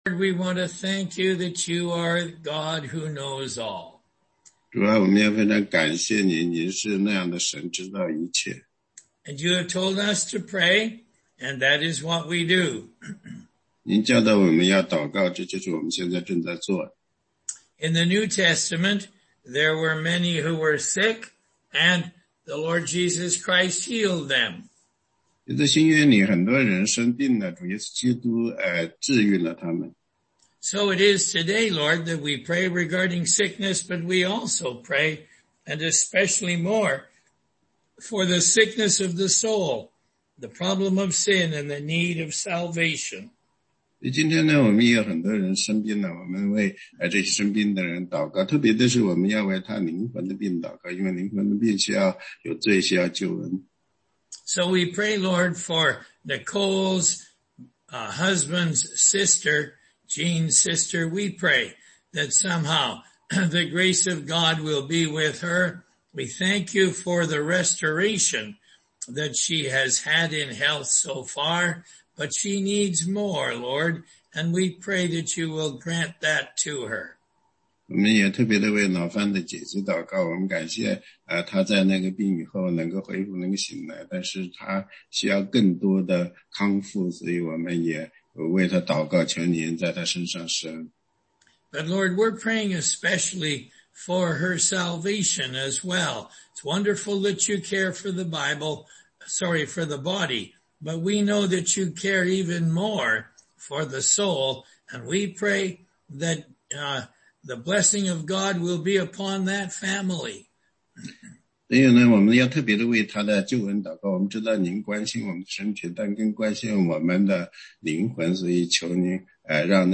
16街讲道录音 - 帖前4章9-12节
答疑课程